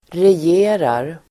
Uttal: [rej'e:rar]